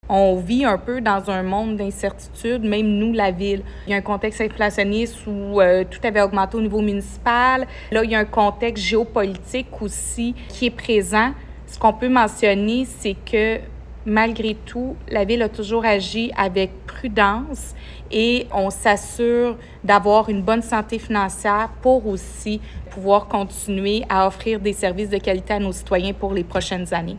Julie Bourdon, mairesse de Granby